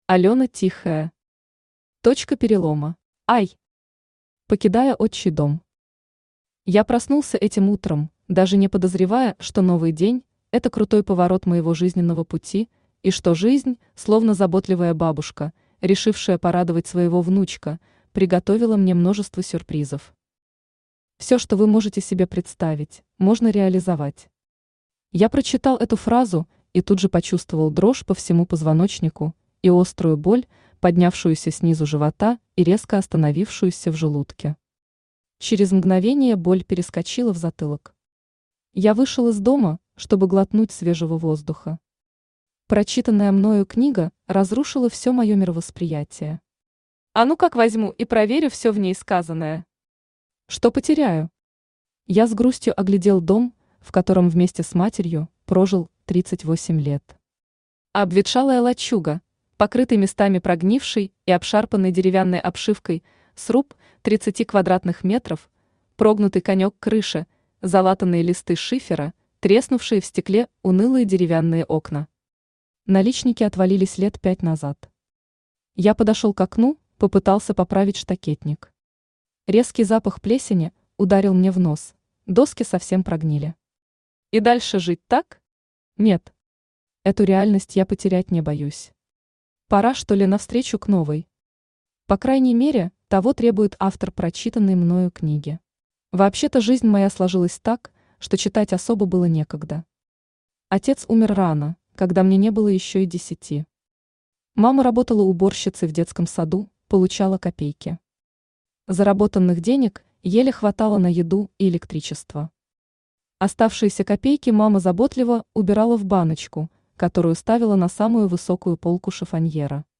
Aудиокнига Точка перелома Автор Алена Тихая Читает аудиокнигу Авточтец ЛитРес.